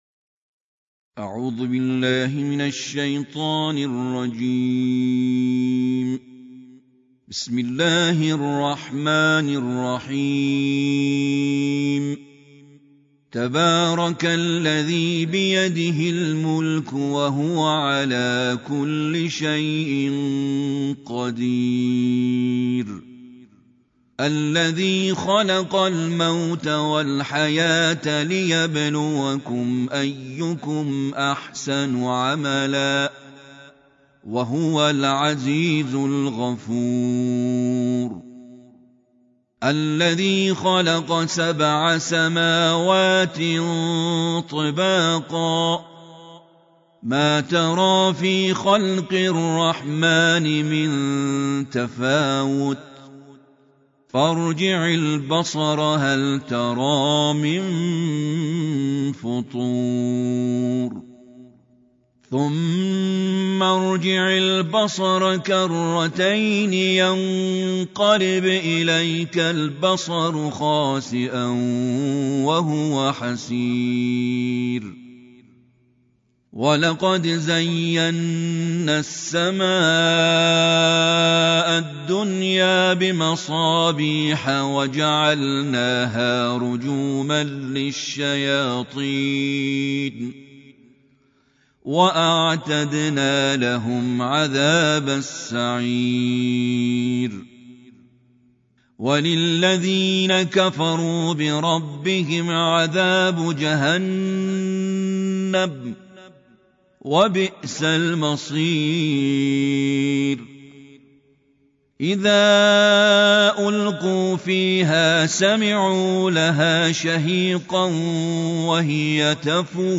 ترتيل القرآن الكريم